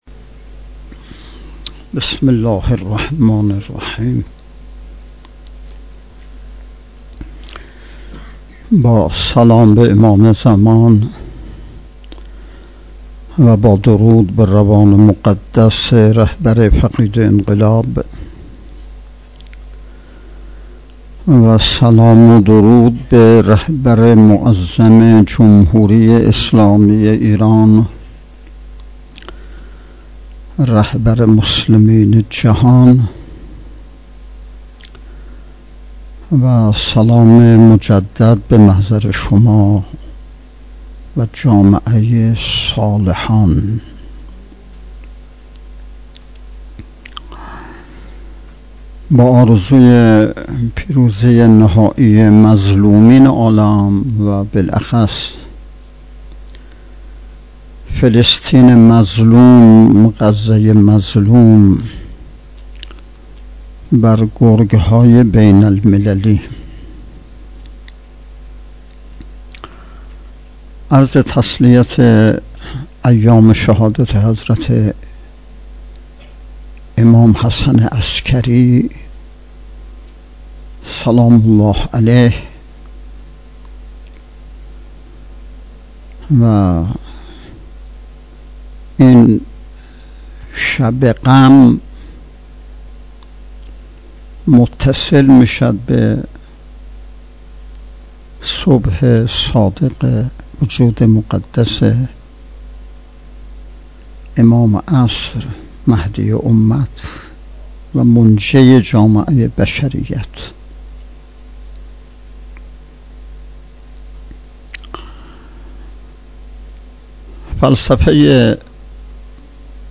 نهمین نشست ارکان شبکه تربیتی صالحین بسیج با موضوع تربیت جوان مؤمن انقلابی پای کار، صبح امروز (۲۱ شهریور) با حضور و سخنرانی نماینده ولی فقیه در استان، برگزار شد.